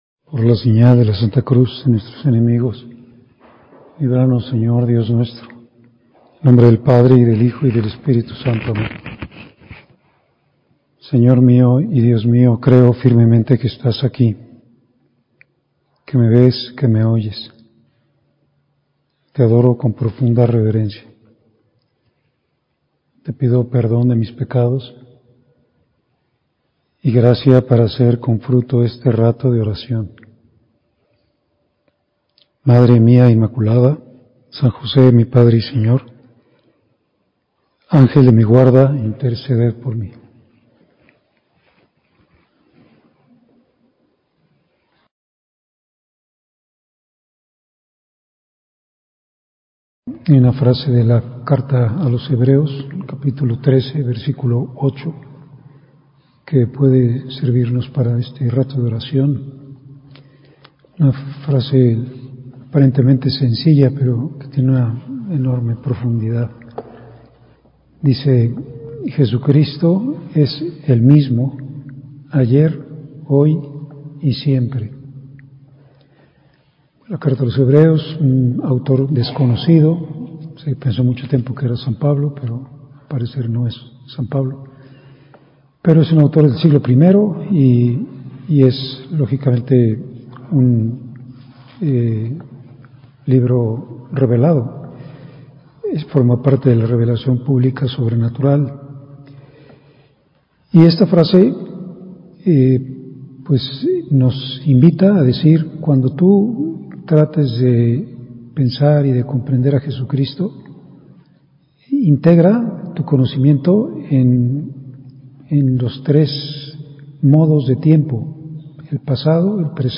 Enseñanza luminosa, que nos cuestiona sobre nuestra percepción de Jesús, nuestro conocimiento personal, con la cabeza y el corazón, de Él. Lo conocemos en el hoy, pero a través de las fuentes, y así lo proyectamos en el Cristo de la eternidad.